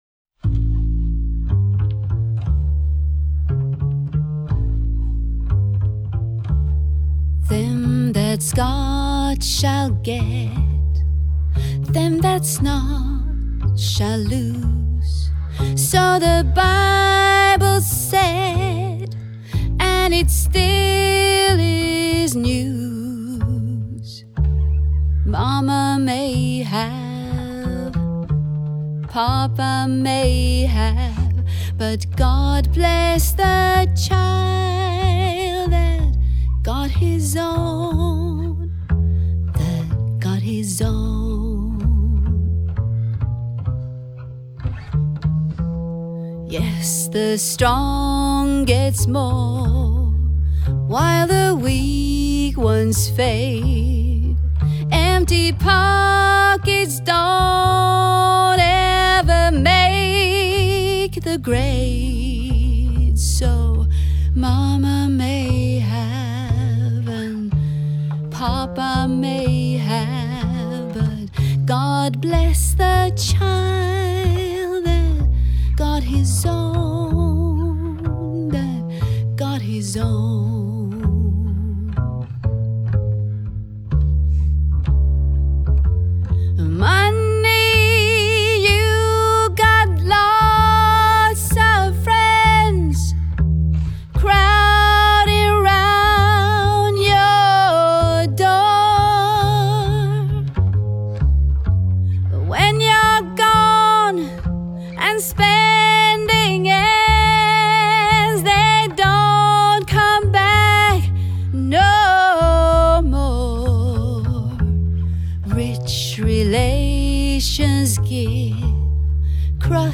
Innspilt og mikset i Musikkloftet studio AS
Recorded and mixed in Musikkloftet Studio AS